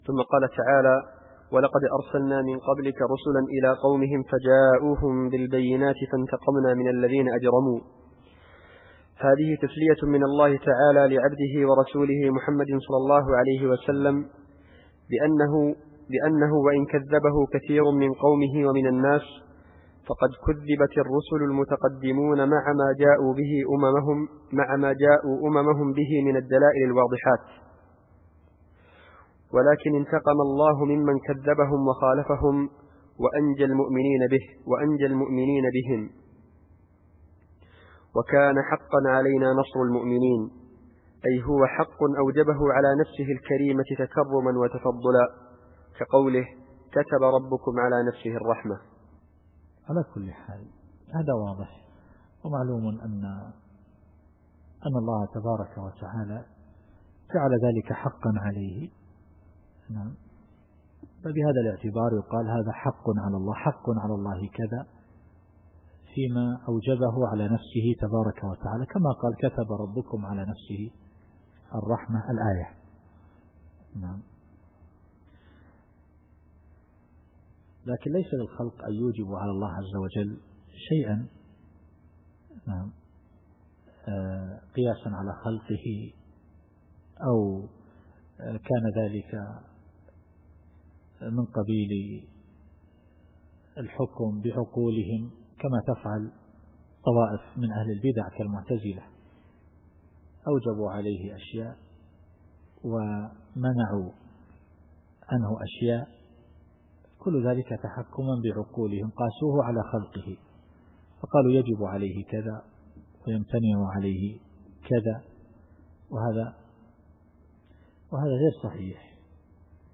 التفسير الصوتي [الروم / 47]